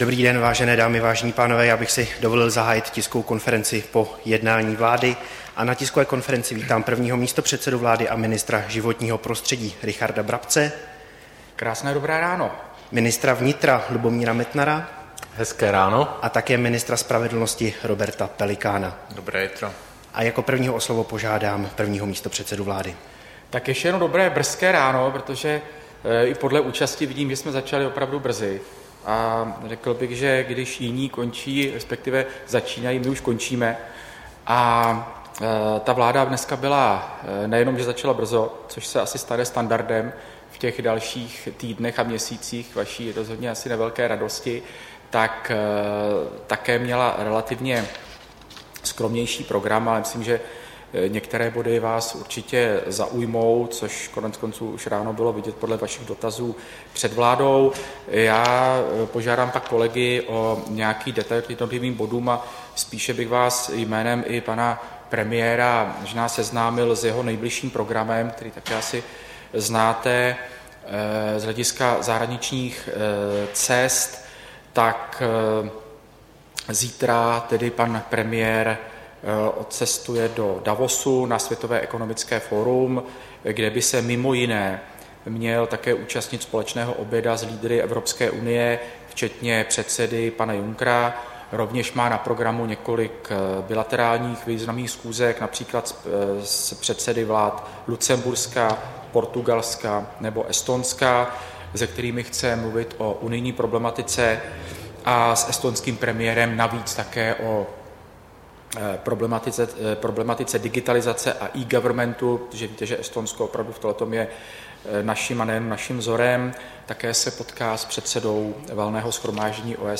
Tisková konference po jednání vlády, 24. ledna 2018